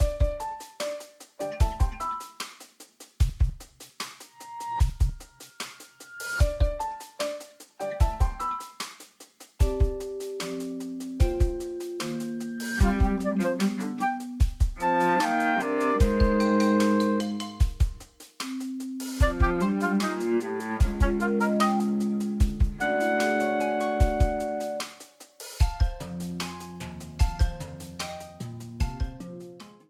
A purple streamer theme
Ripped from the game
clipped to 30 seconds and applied fade-out